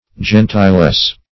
Search Result for " gentilesse" : The Collaborative International Dictionary of English v.0.48: Gentilesse \Gen`ti*lesse"\, n. [OF. gentilesse, gentelise, F. gentillesse.